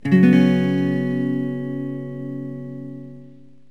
Cs7sus4.mp3